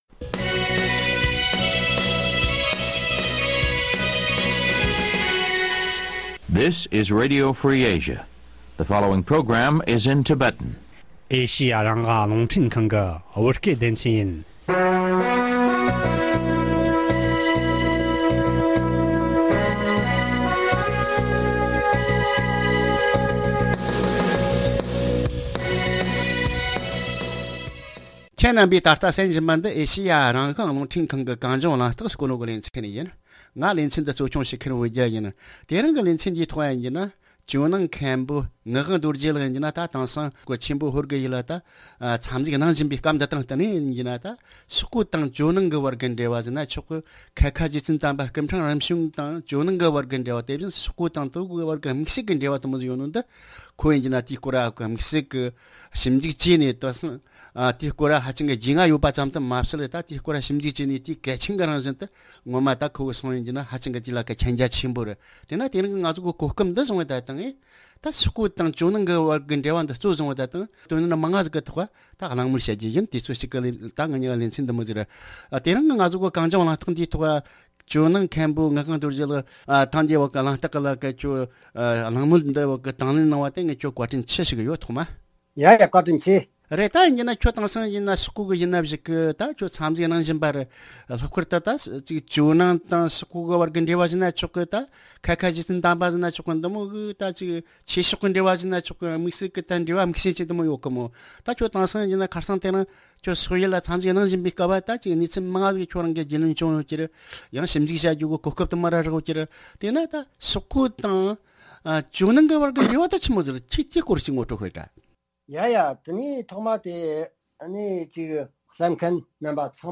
གླེང་མོལ་བྱས།